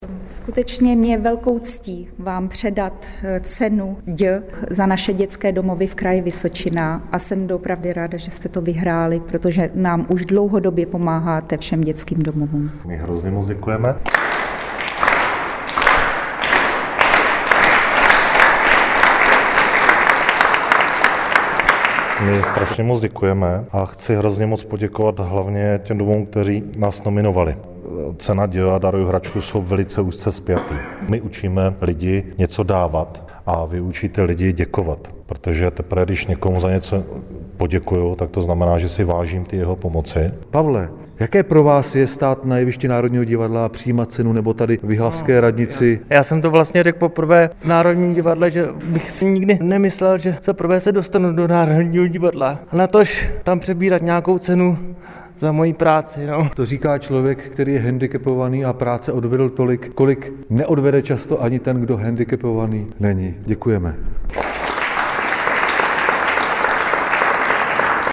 V Gotickém sále historické radnice města Jihlavy 9. dubna převzala „cenu Ď v kraji Vysočina 2015“ společnost Tango Havlíčkův Brod“, kterou nominovalo šest dětských domovů.